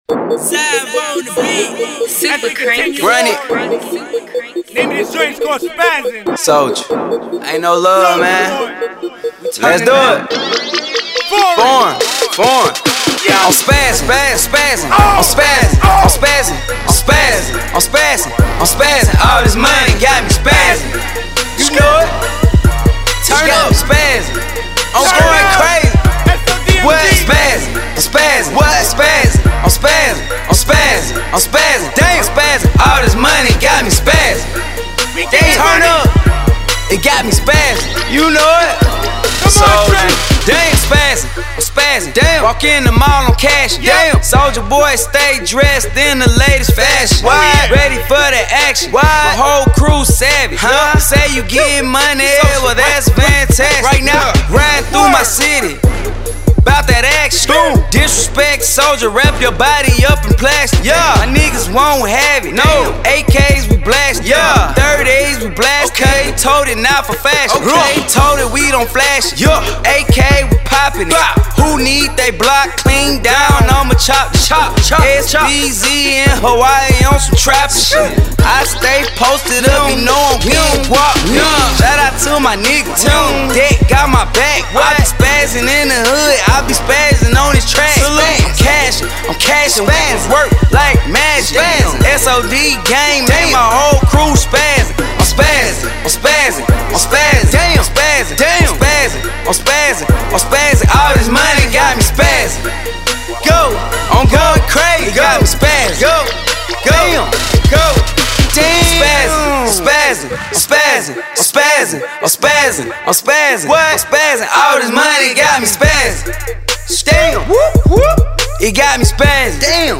Estilo: Hip-Hop/ R&B